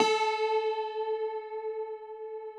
53r-pno13-A2.wav